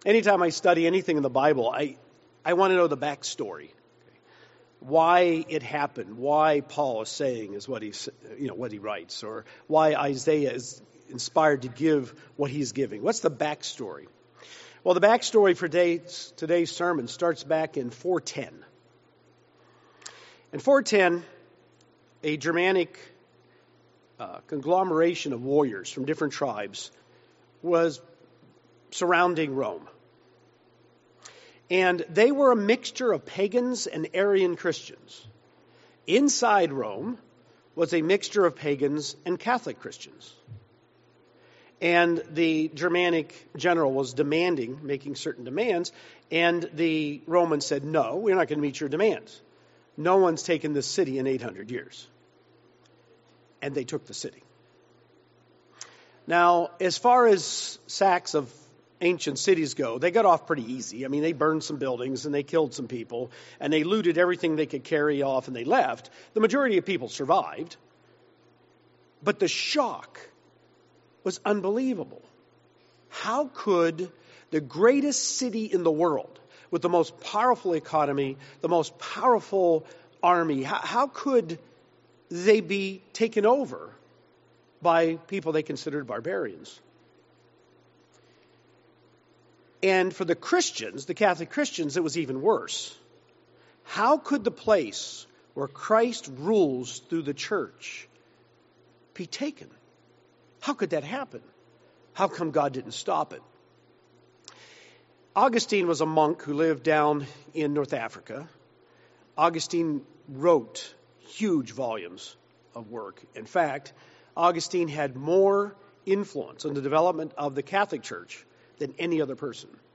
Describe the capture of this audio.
This sermon was given at the Branson, Missouri 2022 Feast site.